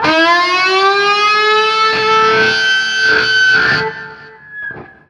DIVEBOMB 7-R.wav